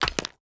Chopping and Mining
mine 1.ogg